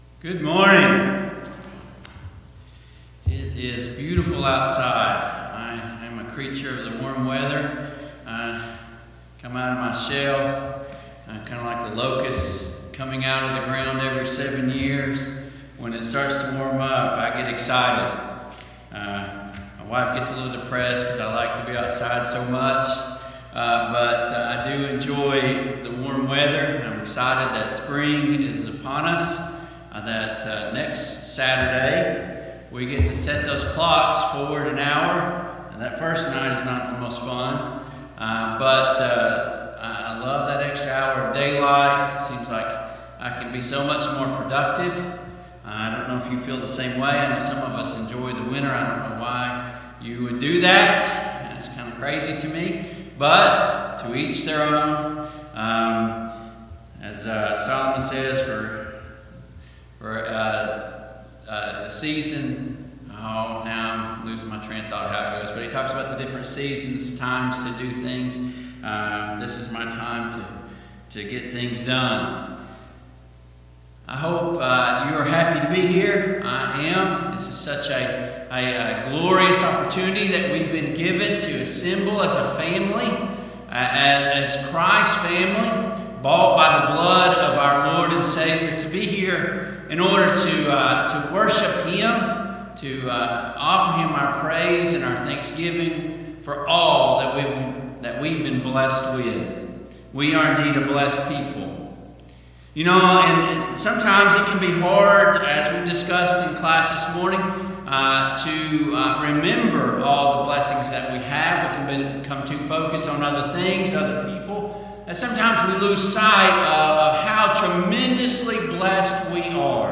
Service Type: AM Worship Topics: Apathy , Spiritual , Zeal , Zealous Faith